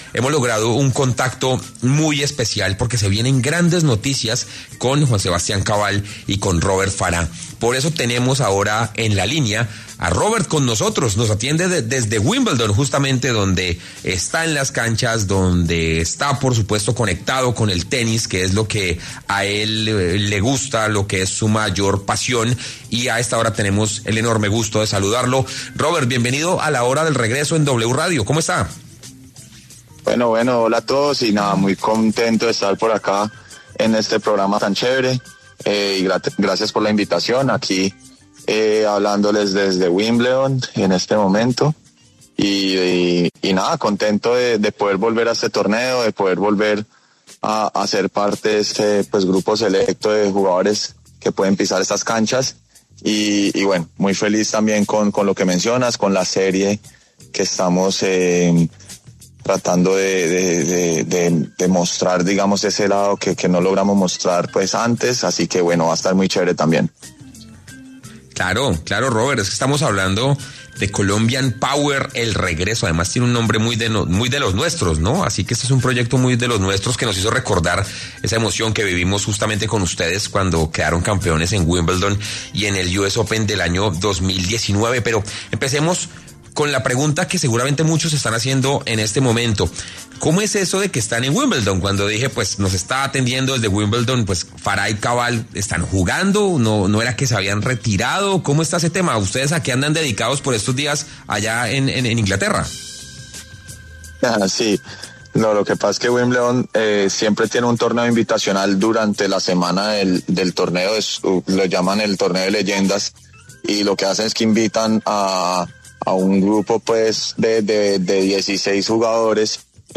Robert Farah pasó por los micrófonos de La Hora del Regreso para hablar sobre el lanzamiento de la serie documental, contando algunos detalles de lo que ha sido su vida desde su retiro.